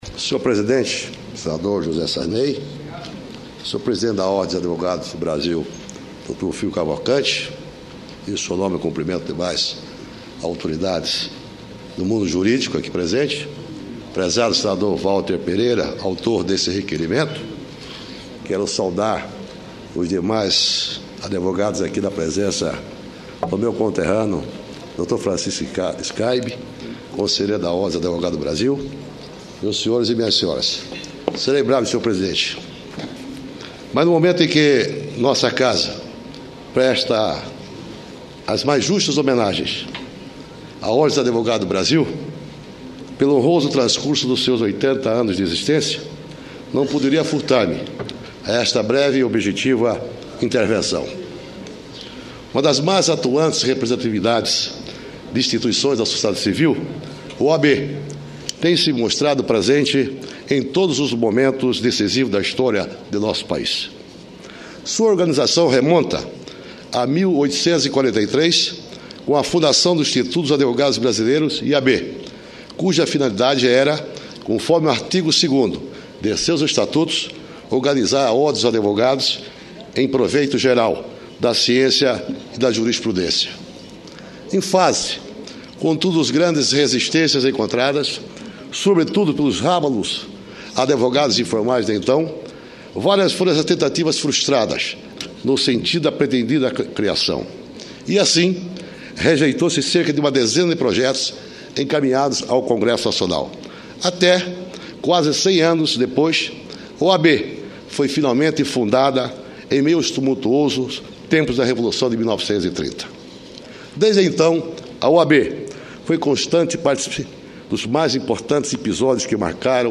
Discurso do senador Jayme Campos
Plenário